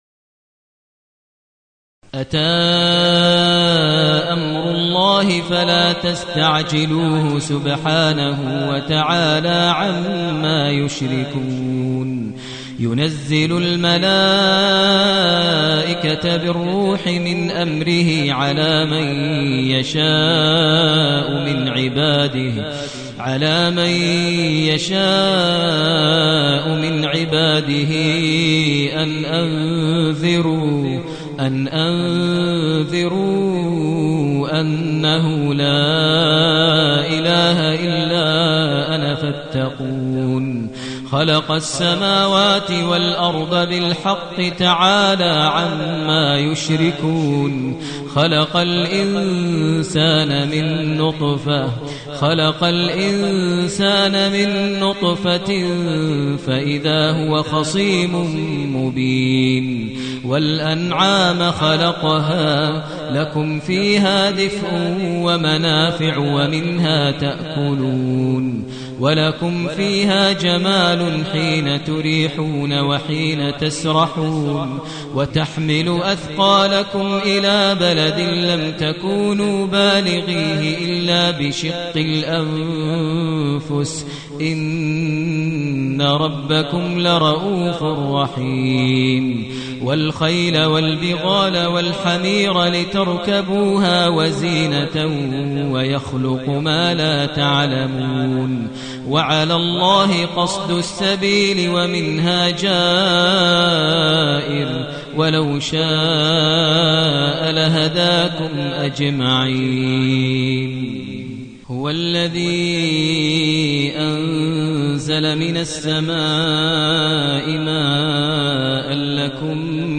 المكان: المسجد النبوي الشيخ: فضيلة الشيخ ماهر المعيقلي فضيلة الشيخ ماهر المعيقلي النحل